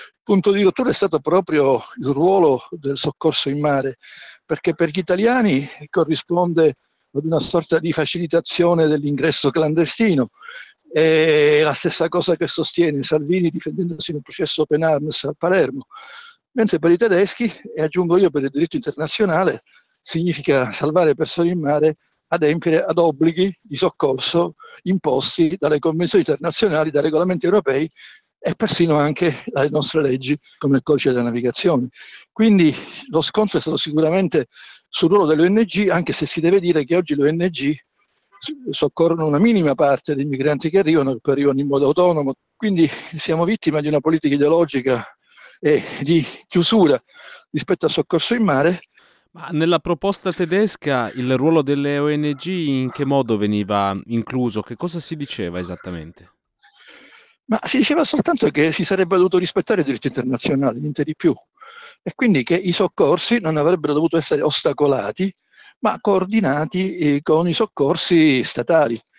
avvocato, esperto di immigrazione